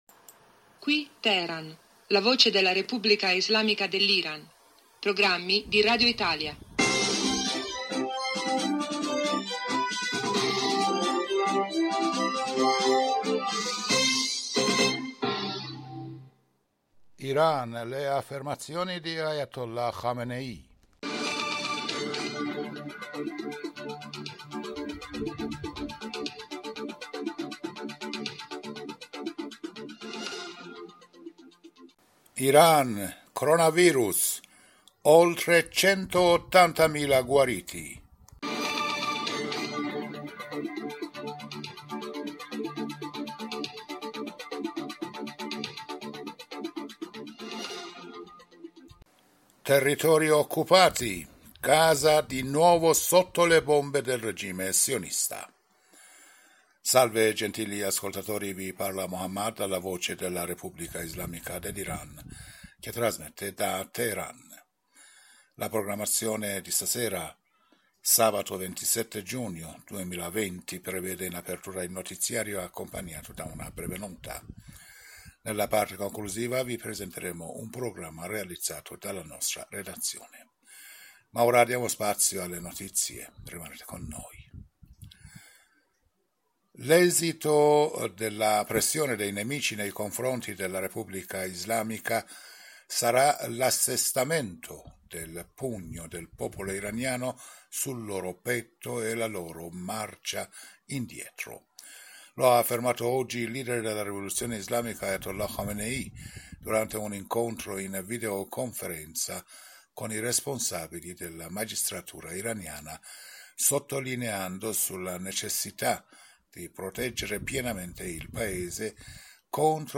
Giornale radio serale del 27 giugno 2020